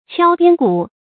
敲边鼓 qiāo biān gǔ
敲边鼓发音
成语注音ㄑㄧㄠ ㄅㄧㄢ ㄍㄨˇ